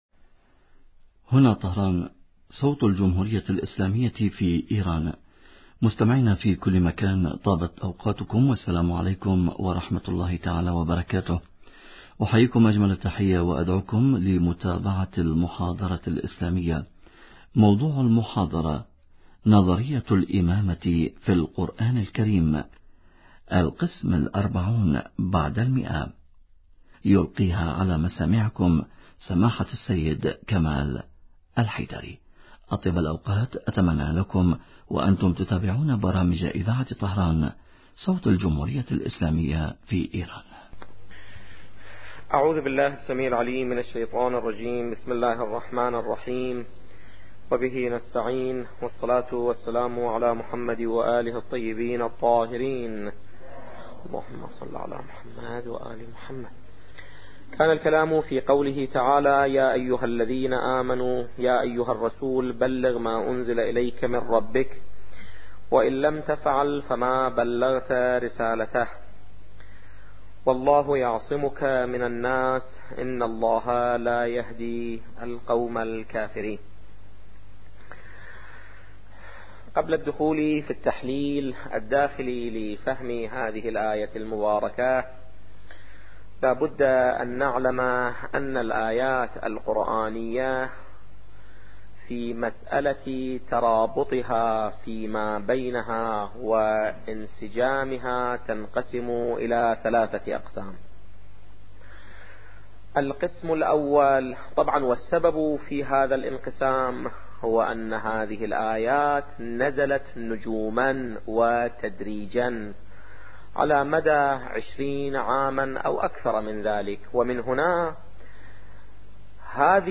نظرية الامامة في القران الكريم - الدرس الاربعون بعد المئة